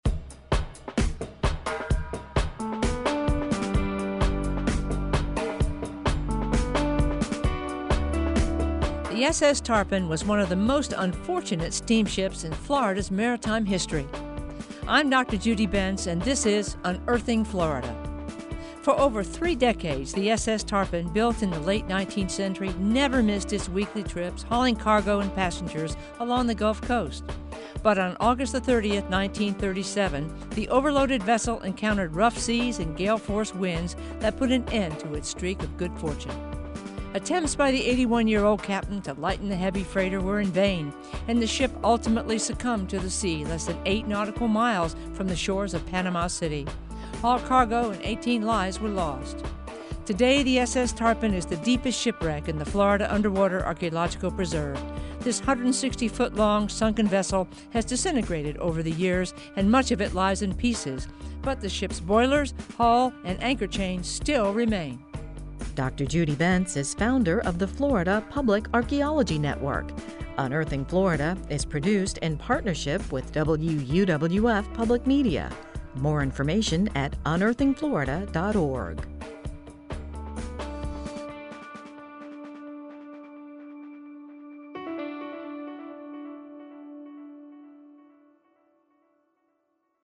Written, narrated, and produced by the University of West Florida, the Florida Public Archaeology Network and WUWF Public Media.